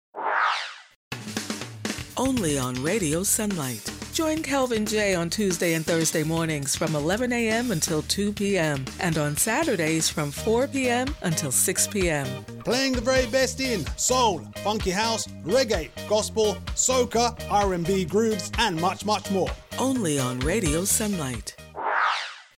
Voice Over Artist